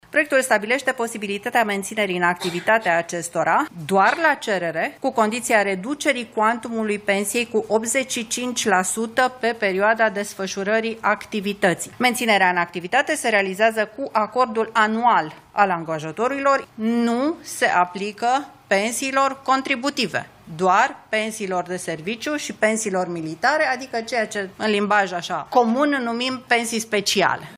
Toți cei care beneficiază de pensii de serviciu sau militare vor putea lucra și după ieșirea la pensie, dacă depun o cerere, dar pensia va fi diminuată cu 85%. La finalul ședinței de la Palatul Victoria